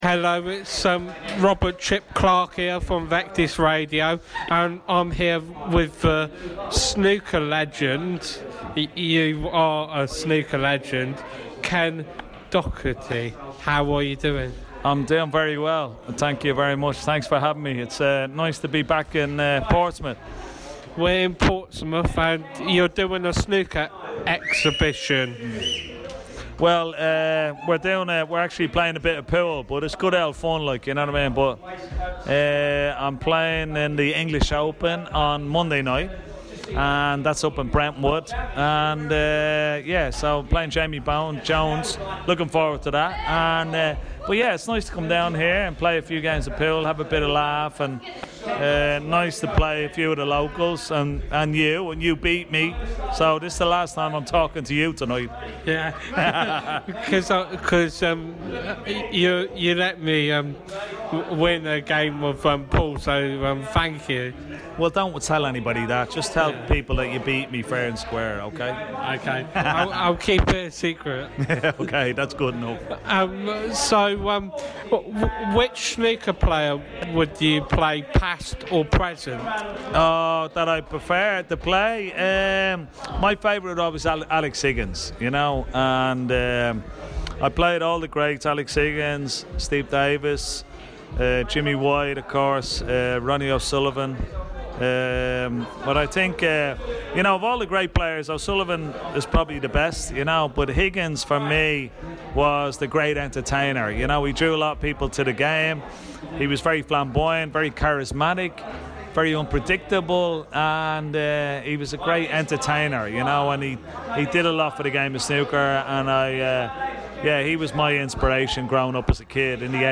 Ken Doherty Snooker legend interview 2022 at The Hampshire Rose